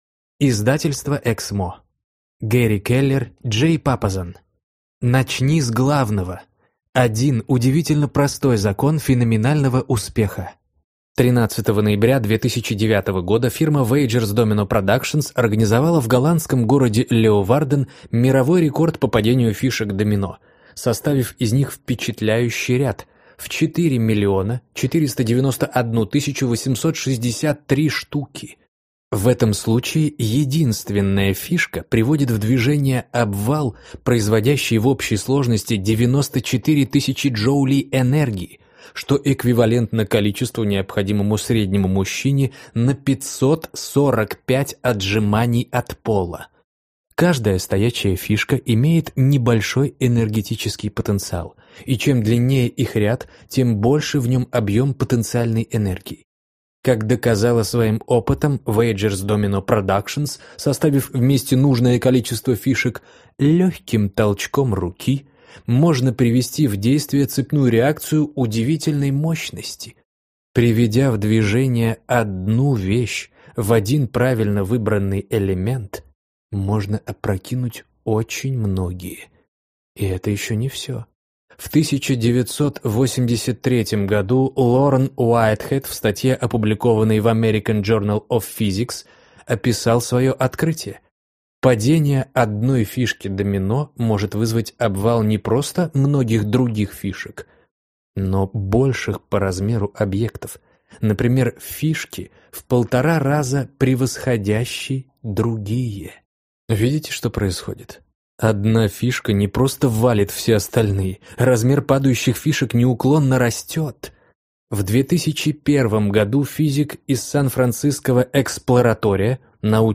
Аудиокнига Начни с главного! 1 удивительно простой закон феноменального успеха | Библиотека аудиокниг